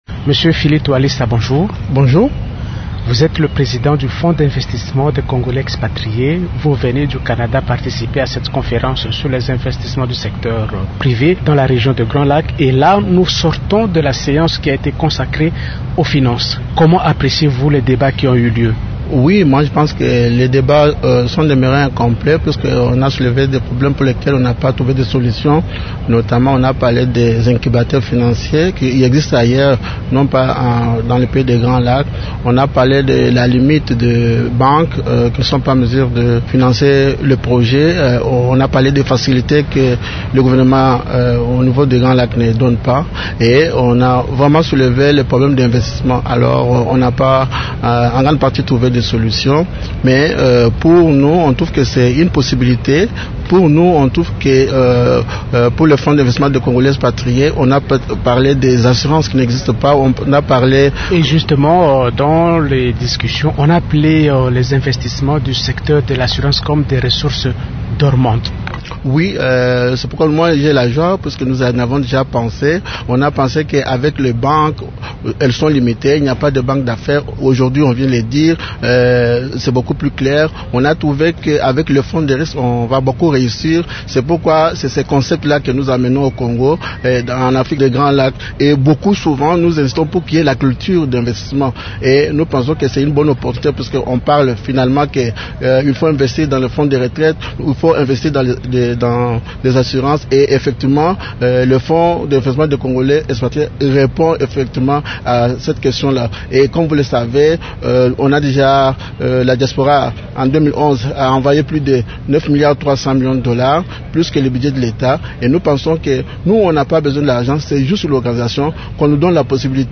L'invité du jour, Émissions / Mgr José Moko, Paix, RDC, Aceac